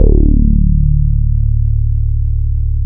BIGARRE BASS.wav